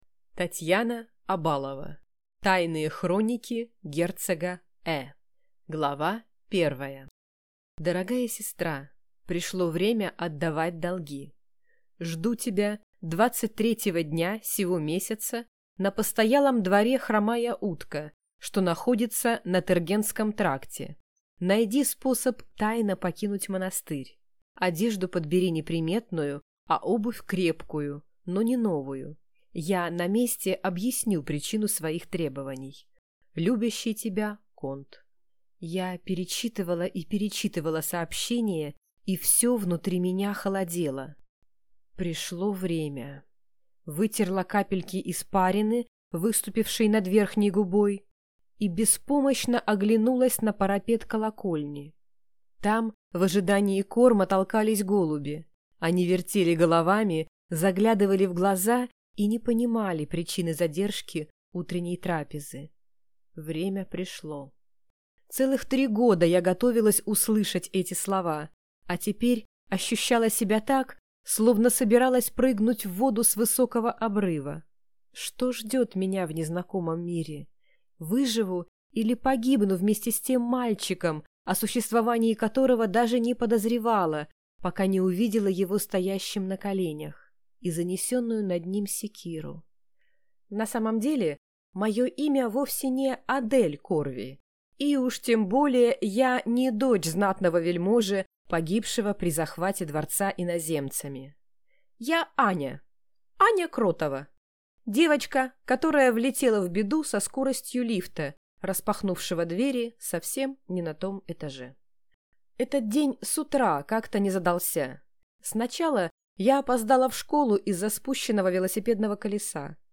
Аудиокнига Тайные хроники герцога Э | Библиотека аудиокниг